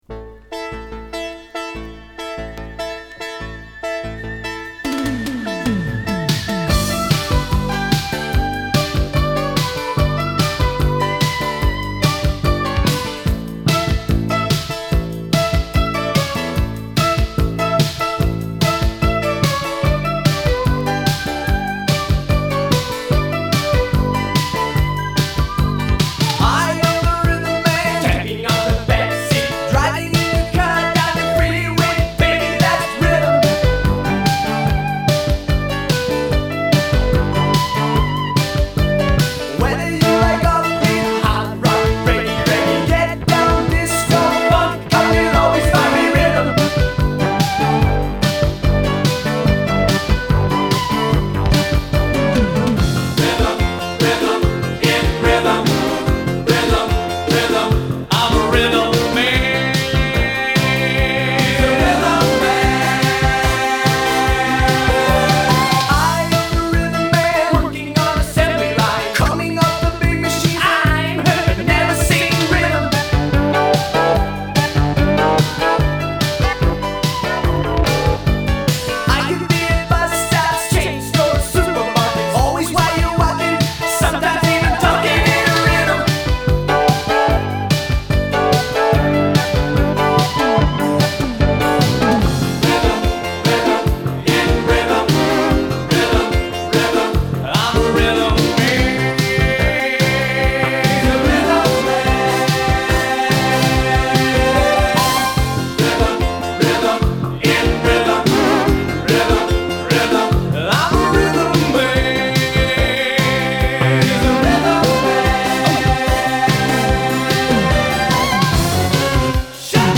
I ripped it from my original vinyl copy this very morning.